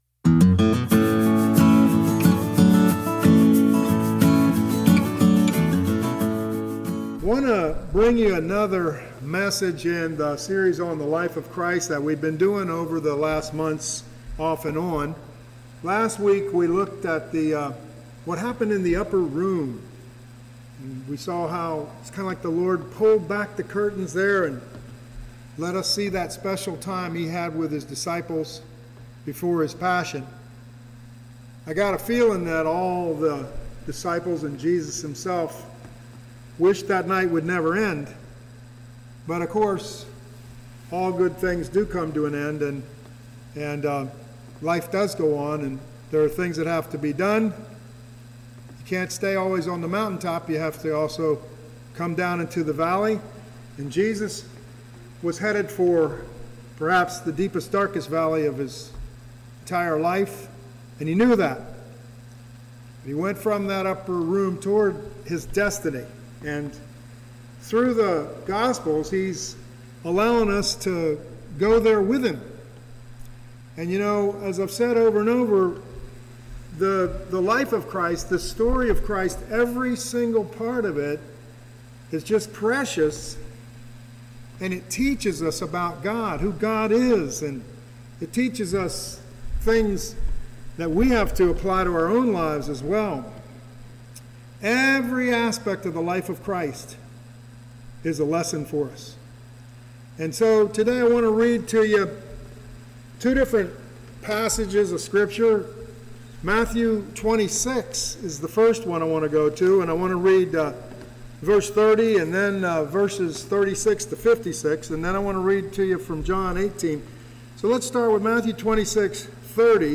There’s a fan buzzing in the background of this message.